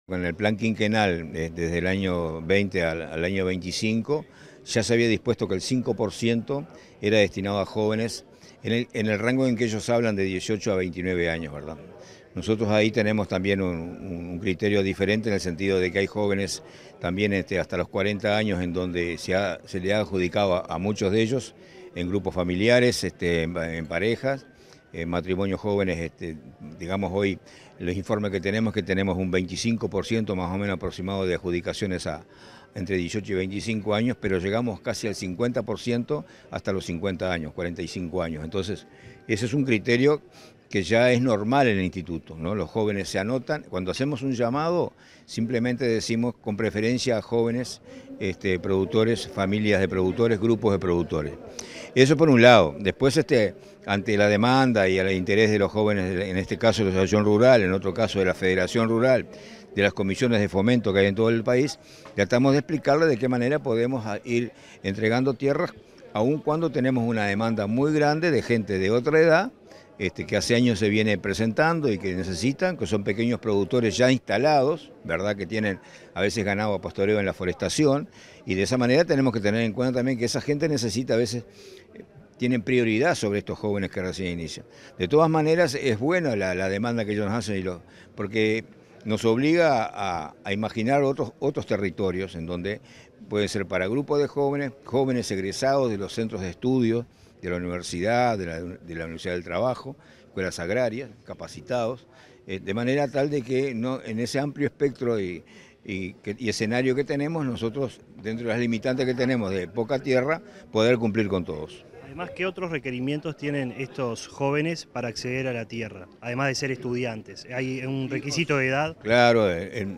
Entrevista al presidente del INC, Julio Cardozo
En el marco de la Expo Prado 2023, este 11 de setiembre, Comunicación Presidencial dialogó con el presidente del Instituto Nacional de Colonización